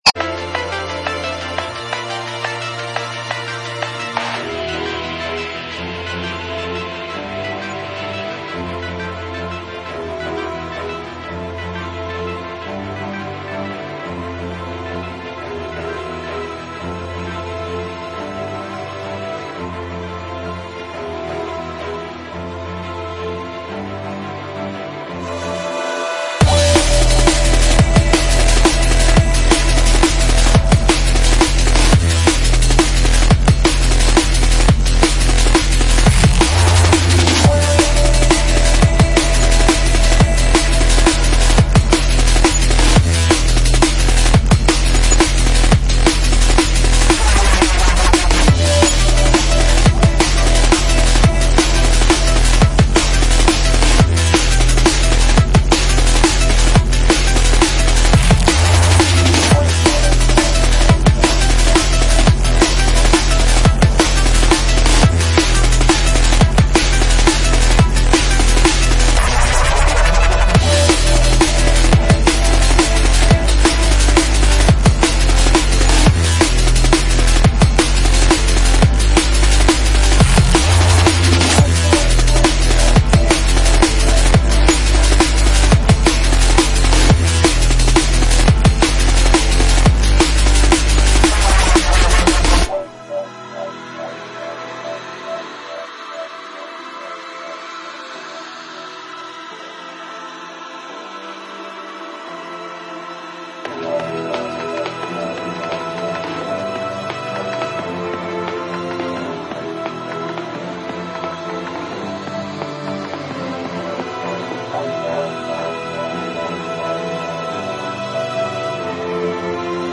Drum and Bass / Jungle